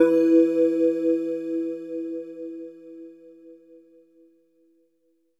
LEAD E3.wav